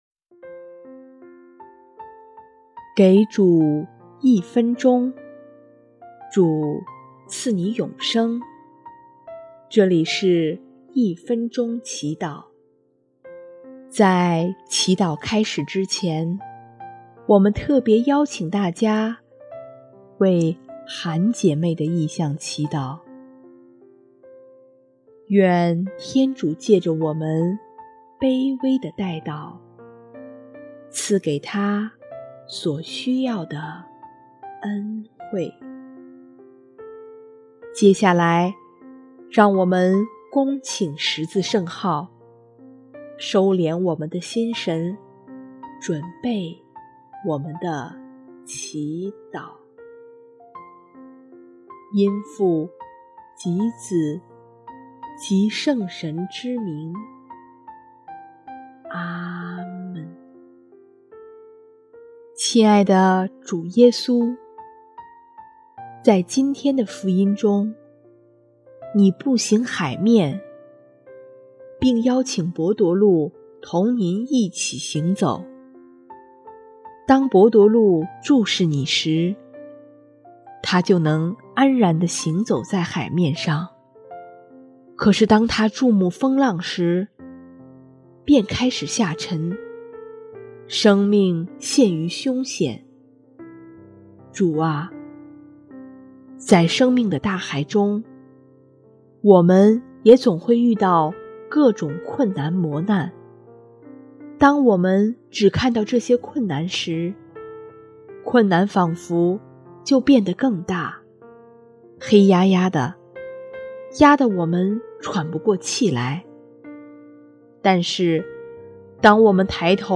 【一分钟祈祷】|8月13日 仰望主耶稣，不离不弃！